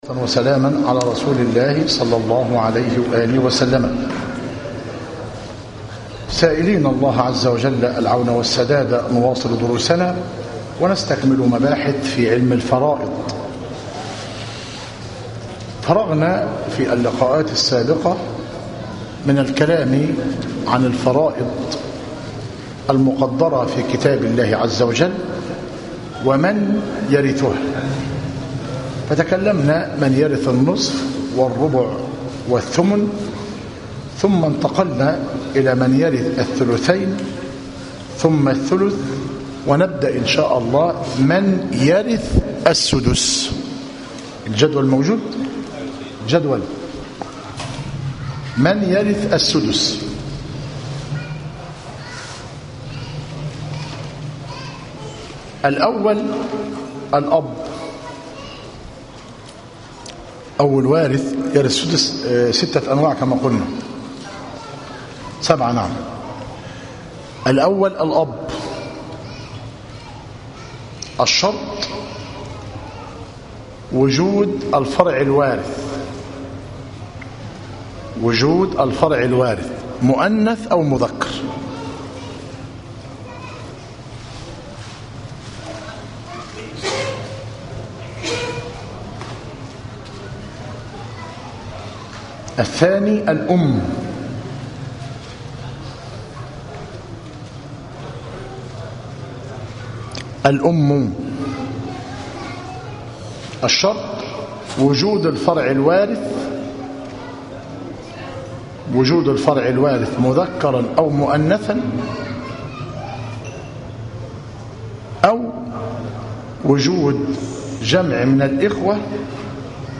علم الفرائض والمواريث - مسجد التوحيد - ميت الرخا - زفتى - غربية - المحاضرة الثامنة والثلاثون - بتاريخ 20- ذو القعدة - 1436هـ الموافق 5- سبتمبر- 2015 م